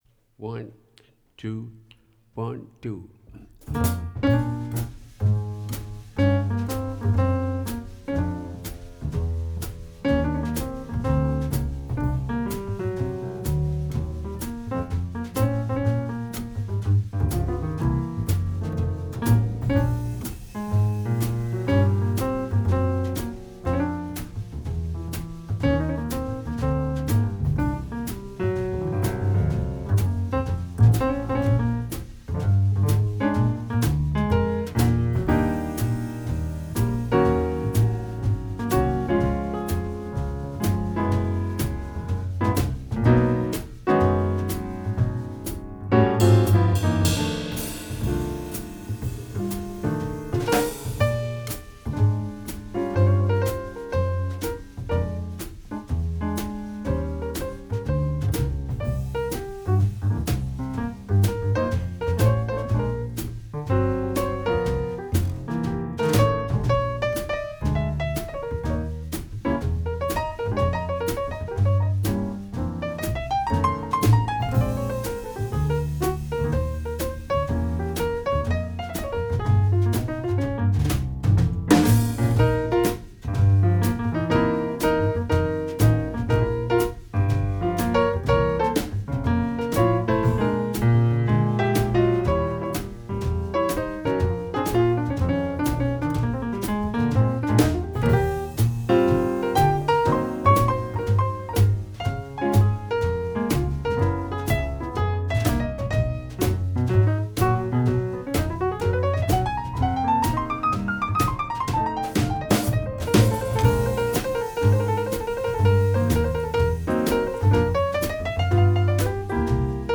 Dabei ist sein Klangbild eher auf der wärmeren Seite.
48 kHz mit Joplin-Wandler
Bandmaschine Studer A80